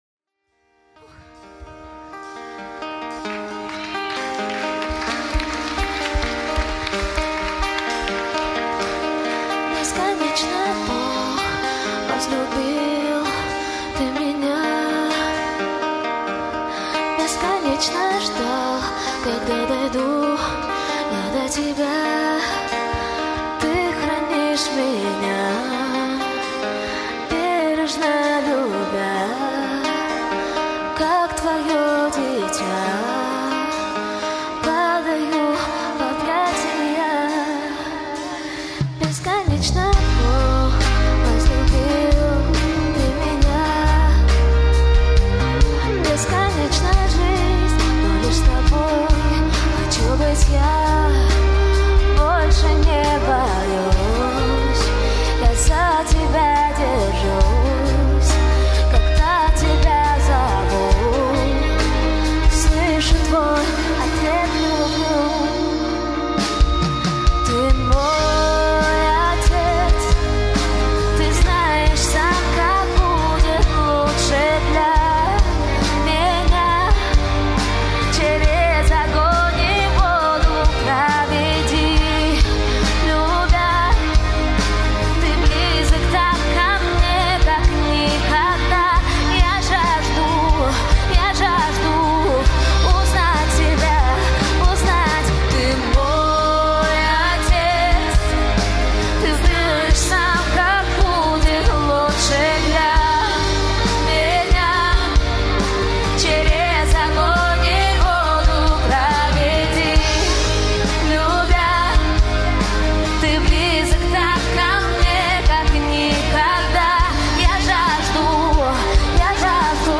песня
15054 просмотра 5836 прослушиваний 1275 скачиваний BPM: 68